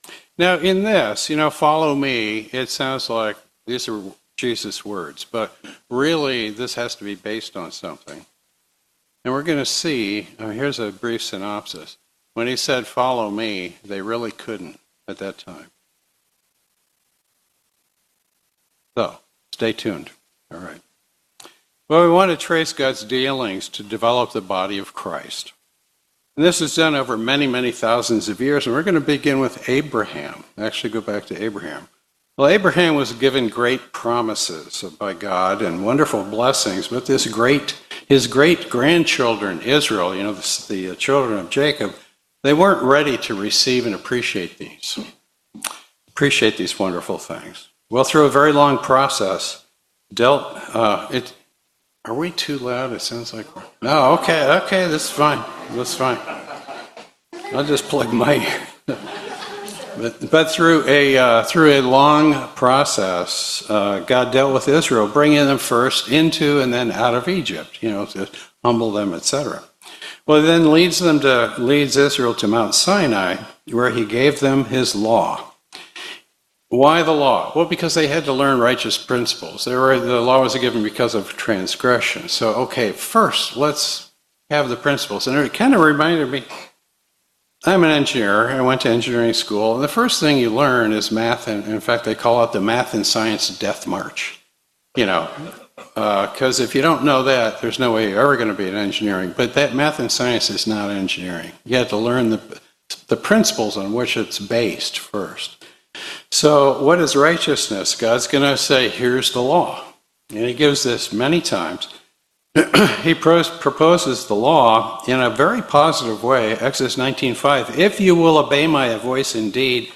Series: 2025 Chicago Memorial Day Convention